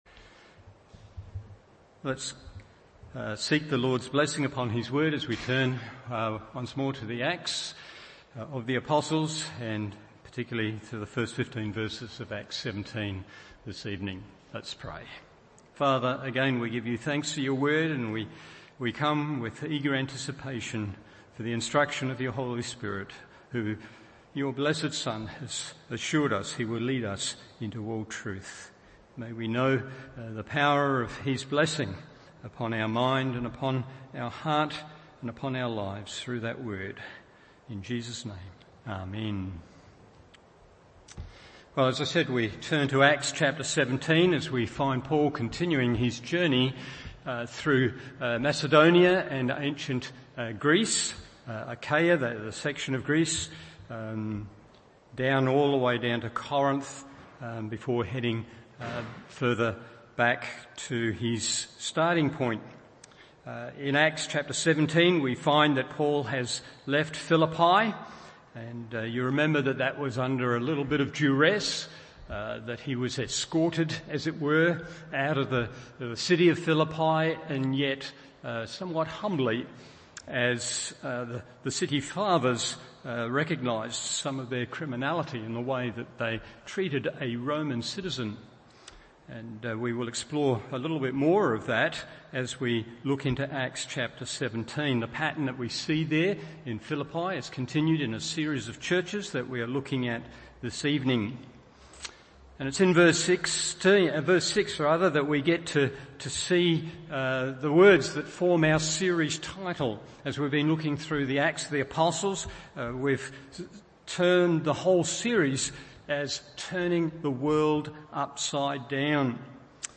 Evening Service Acts 17:1-15 1.